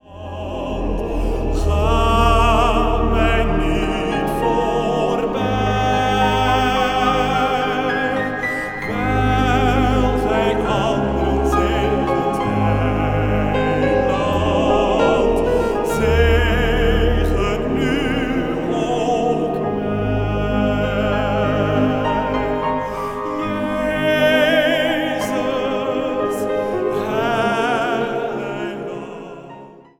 Zang | Jongerenkoor